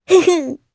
Rephy-Vox_Happy1_jp.wav